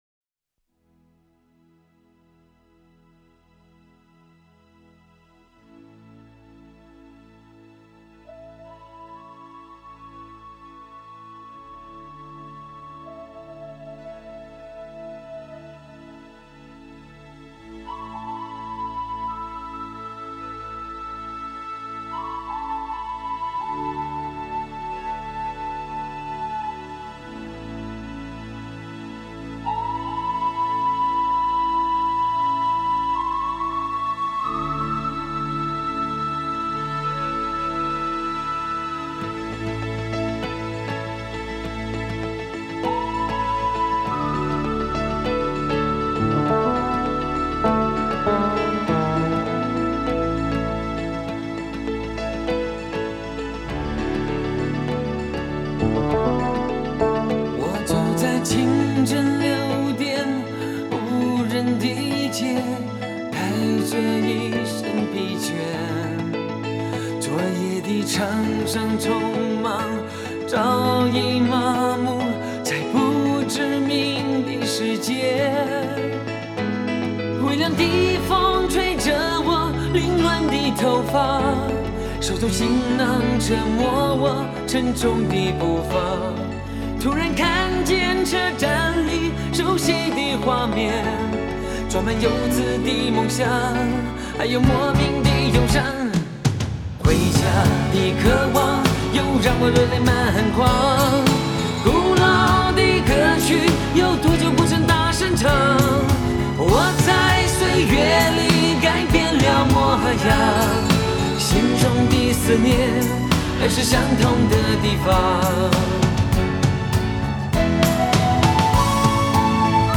电音 收藏 下载